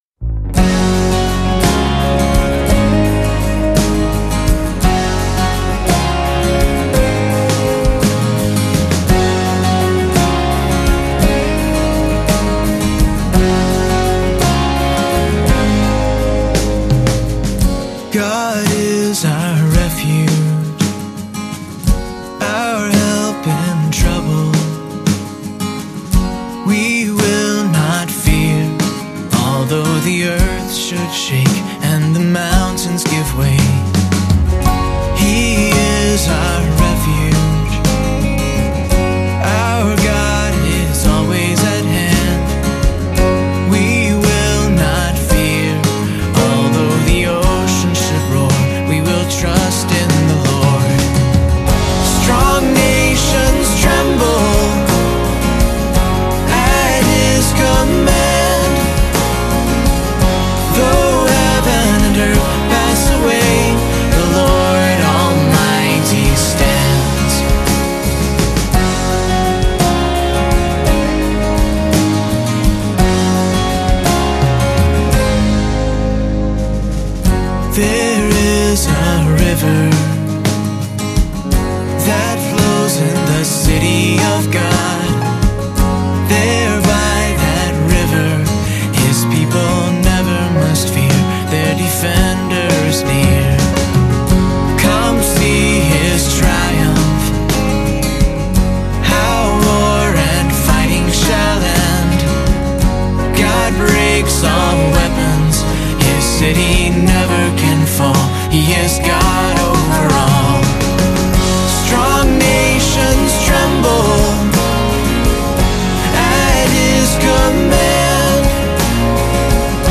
lead vocals, background vocals, acoustic guitars, harmonica
drums, percussion
bass guitar
electric guitars
piano, organ, rhodes, keys
accordian, mandolin, high strung guitar